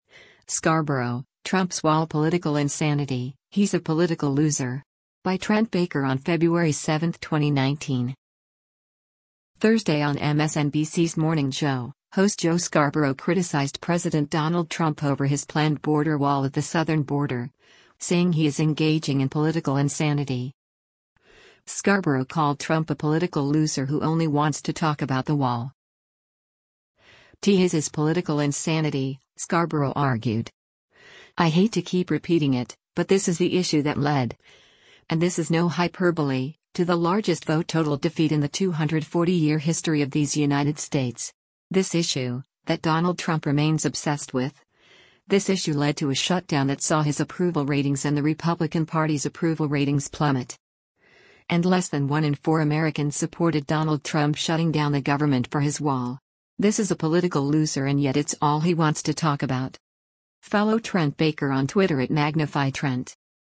Thursday on MSNBC’s “Morning Joe,” host Joe Scarborough criticized President Donald Trump over his planned border wall at the southern border, saying he is engaging in “political insanity.”